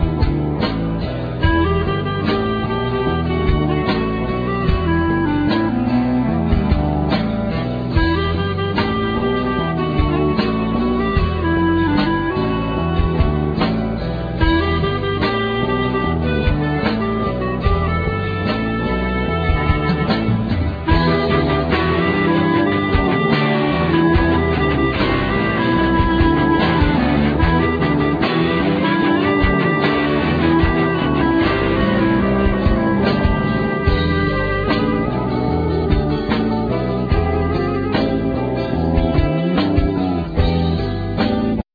Vocal,Mandolin,E+A.Guitar
Saxophone,Clarinet,Whistle
Bass,Cello,Violin
Keyboards